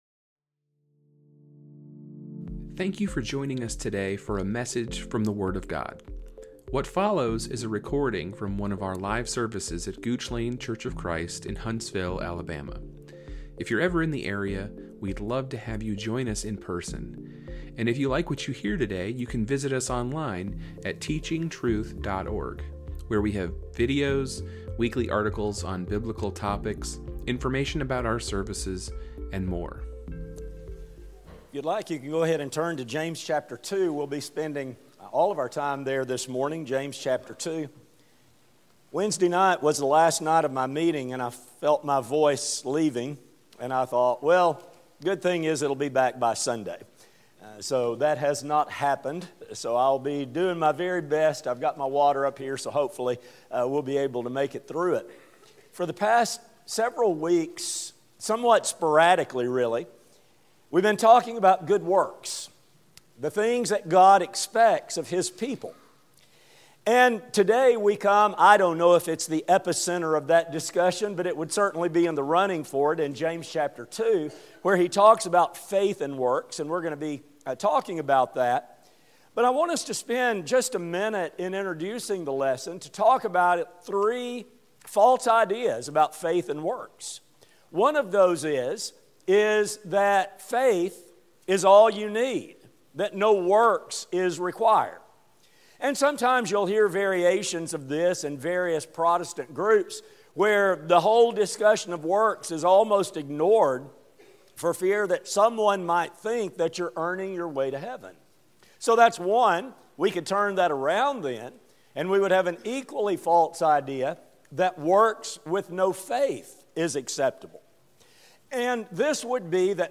This sermon will explore James’ admonitions toward good works. It will demonstrate that, far from drudgery, the opportunity to do good is how we show faith in action.